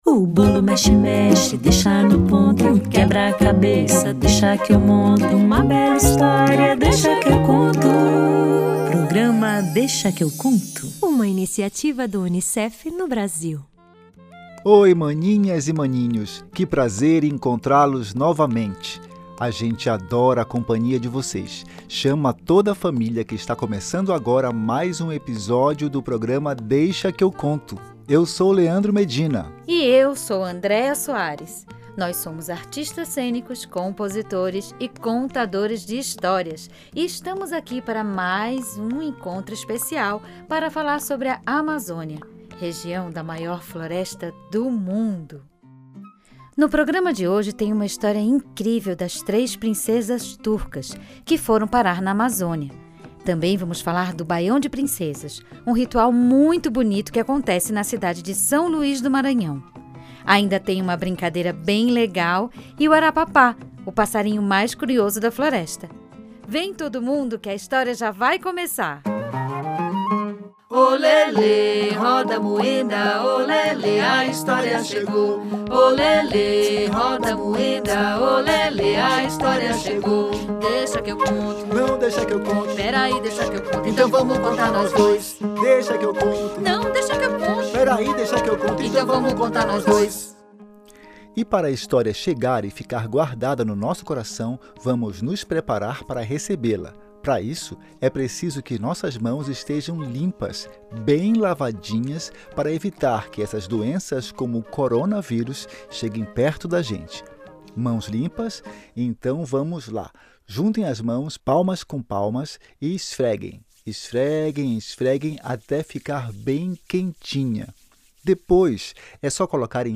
Vamos conhecer também o ritual do Baião de Princesas, que acontece em São Luís do Maranhão, e fazer silêncio para ouvir o canto das araras. Vem, que ainda tem uma brincadeira-surpresa!